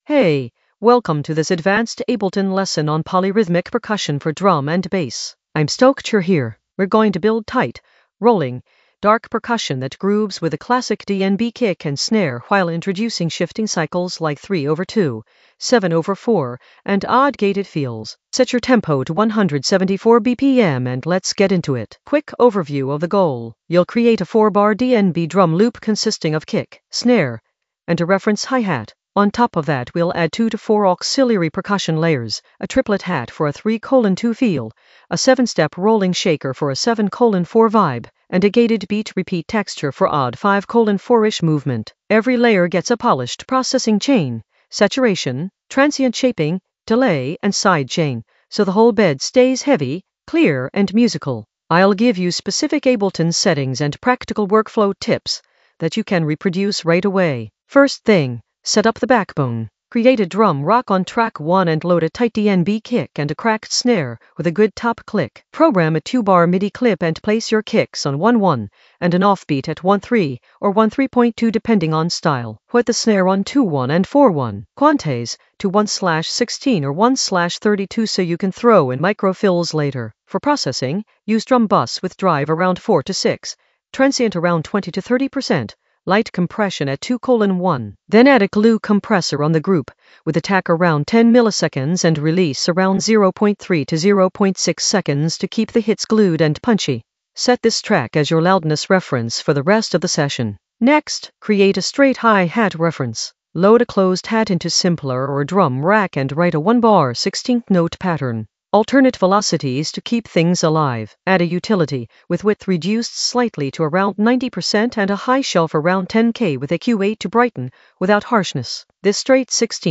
An AI-generated advanced Ableton lesson focused on Polyrhythmic percussion in drum and bass in the Groove area of drum and bass production.
Narrated lesson audio
The voice track includes the tutorial plus extra teacher commentary.
Teacher: energetic, clear, and professional — let’s make your grooves move in weird, heavy ways. ⚡🥁